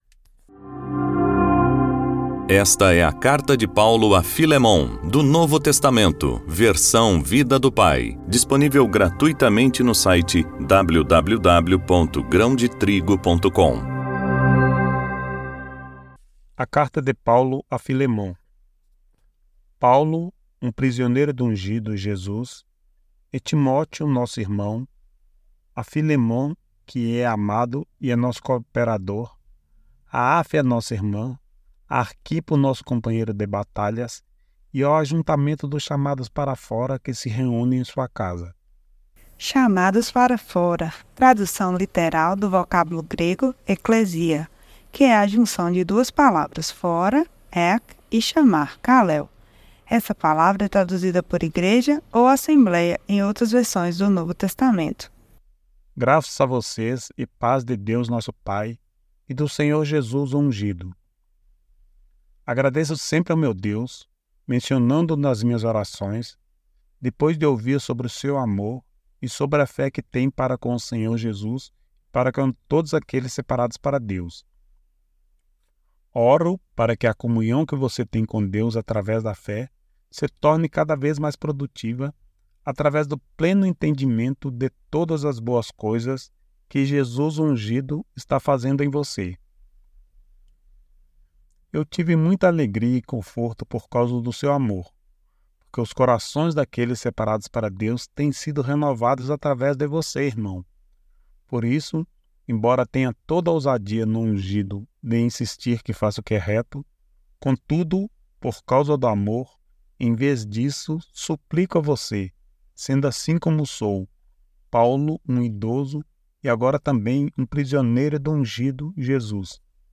voz-audiobook-novo-testamento-vida-do-pai-filemon.mp3